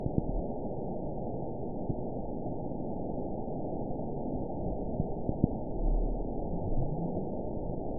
event 922168 date 12/27/24 time 22:24:24 GMT (11 months, 1 week ago) score 9.30 location TSS-AB04 detected by nrw target species NRW annotations +NRW Spectrogram: Frequency (kHz) vs. Time (s) audio not available .wav